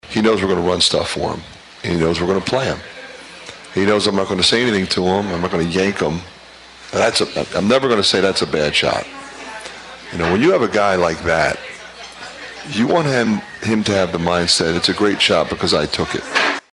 Iowa sophomore Payton Sandfort broke out of his shooting slump with 22 points in a Sunday win at Rutgers. McCaffery says it was only a matter of time.